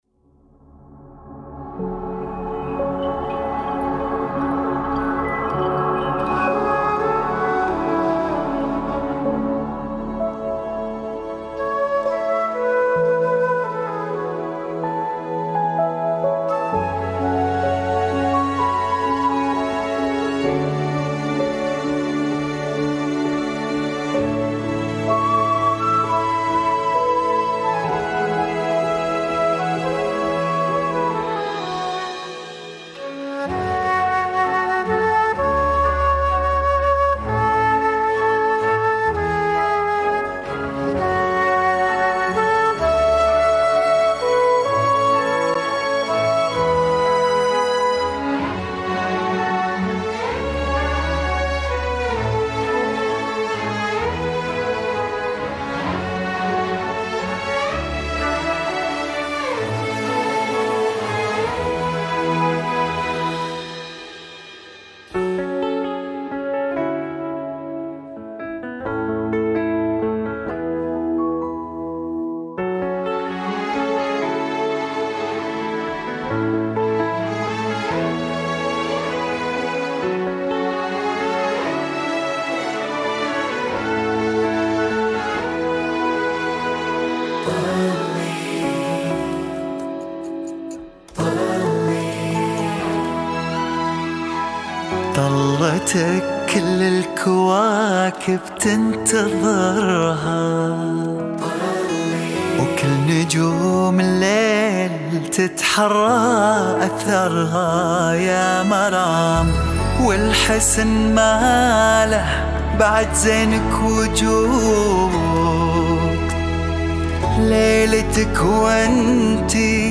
زفة عروس